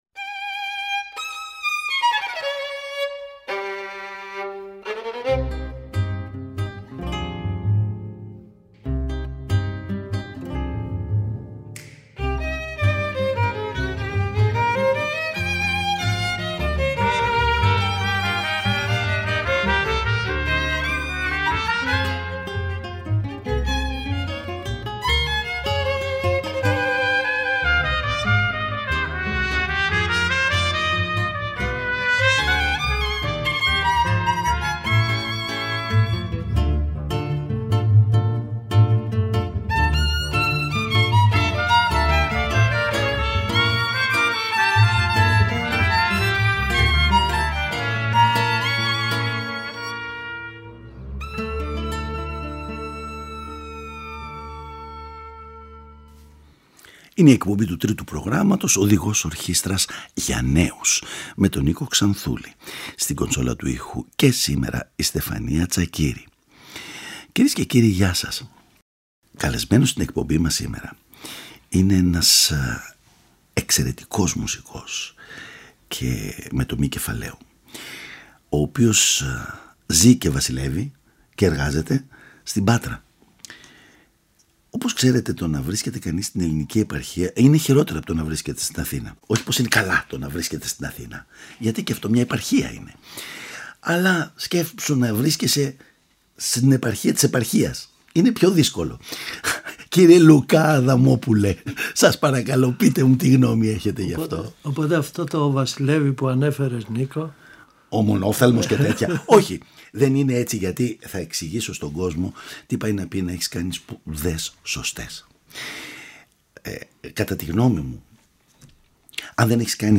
Όμορφες μουσικές εξαίσια παιγμένες θα απολαύσουμε από τους δύο πατρινούς μουσικούς.
Παραγωγή-Παρουσίαση: Νίκος Ξανθούλης